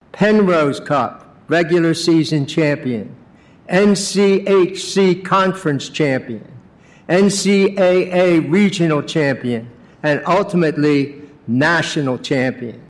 The resolution, read by commissioner Don Cooney included the list of trophies they won.